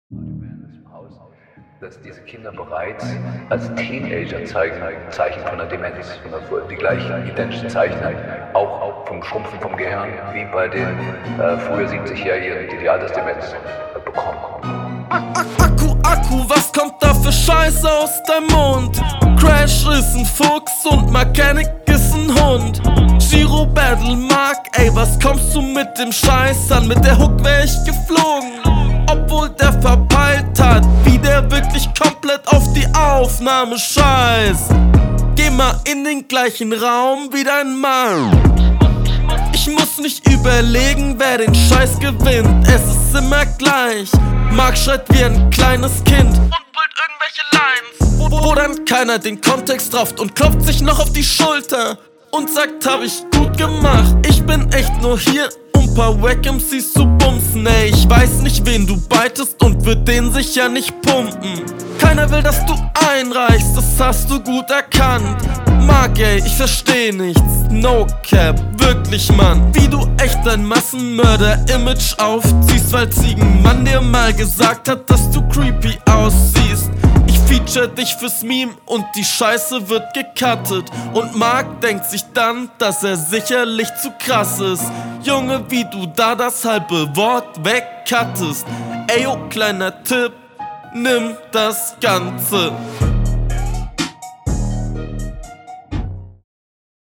Flowlich besser als dein Gegner aber irgendwie auch nix eigenes.